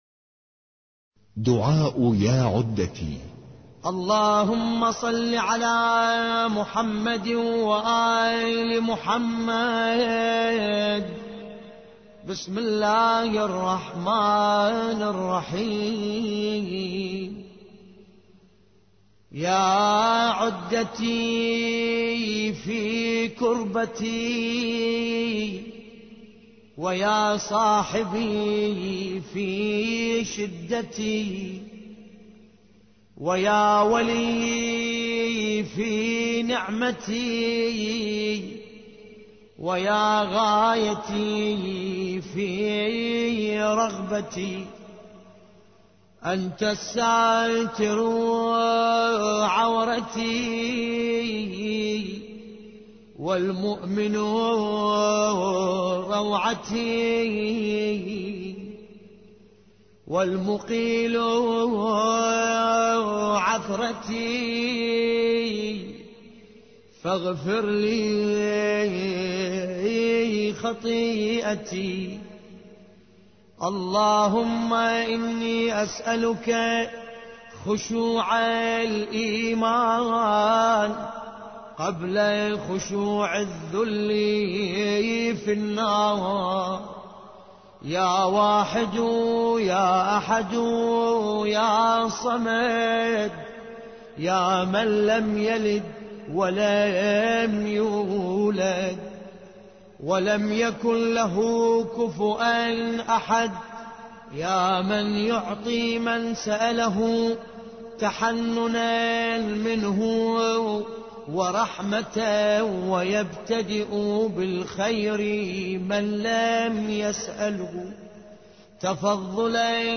ملف صوتی دعاء ياعدتي بصوت باسم الكربلائي